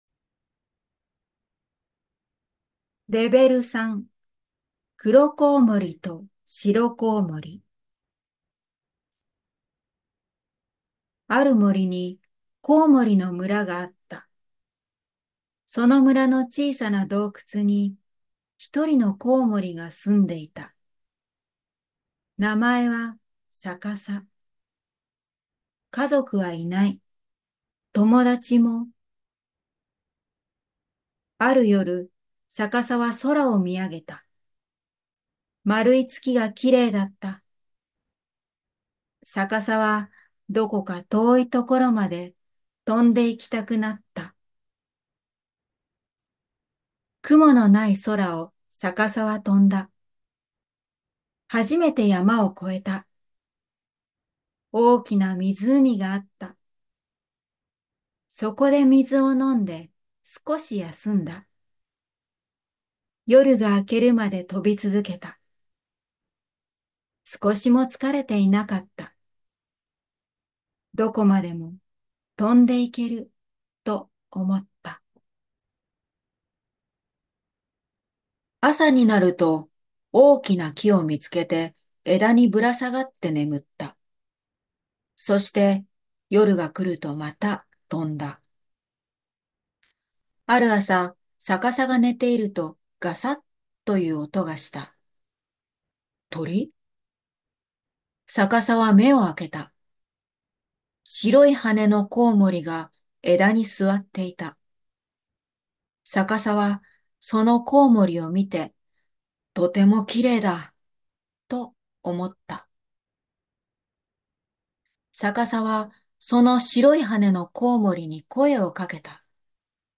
朗読音声付き
落ち着いている声を聞きながら、本を読めました。